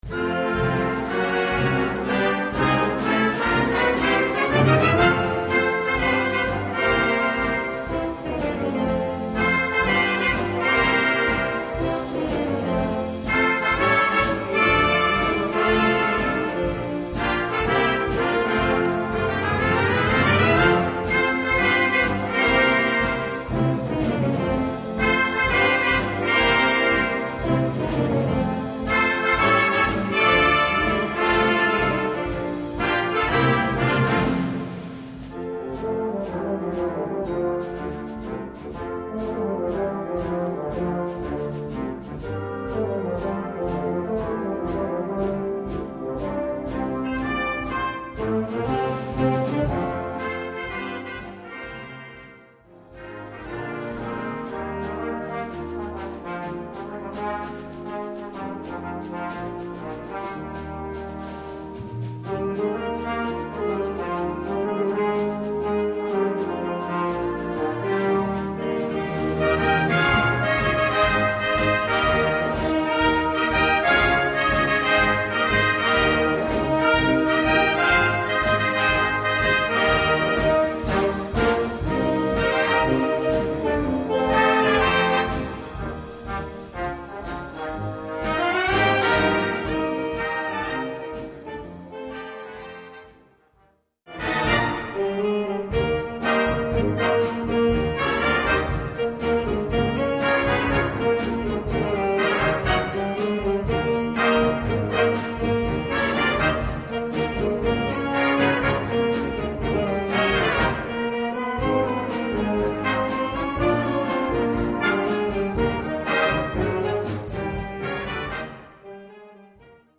Gattung: Medley
Besetzung: Blasorchester